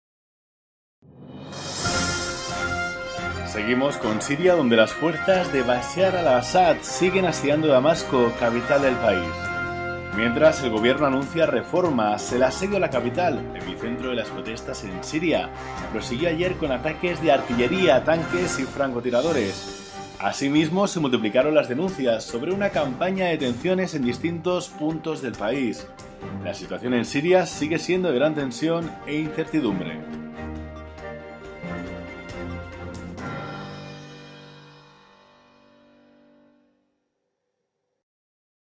Spanish professional announcer
kastilisch
Sprechprobe: Industrie (Muttersprache):